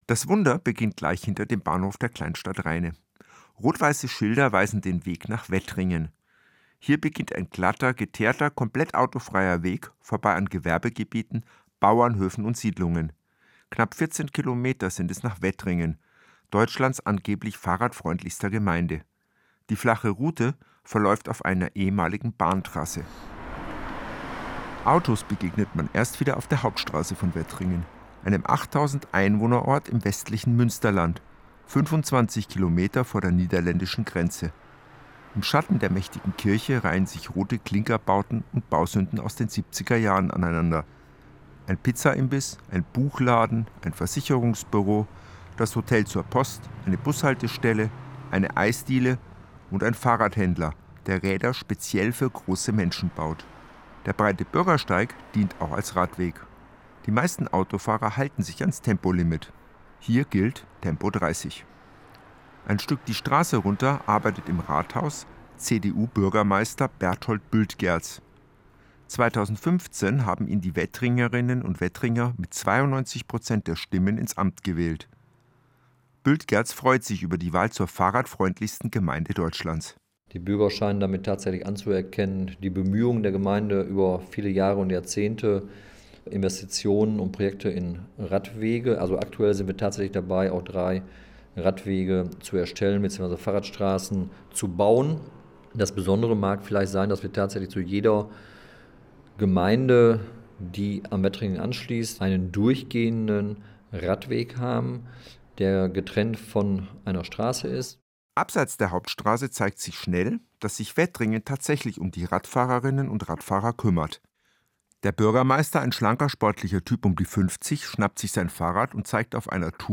Meine Radio-Reportage im Deutschlandfunkkultur: